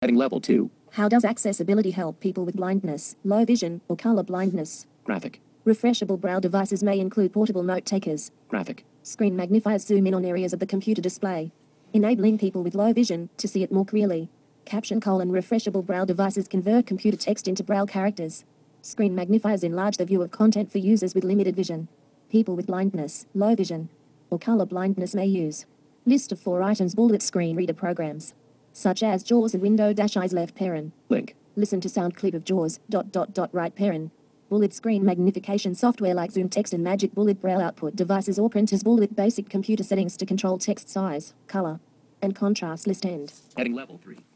Listen to sound clip of JAWS...);
jaws-example.mp3